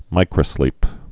(mīkrə-slēp)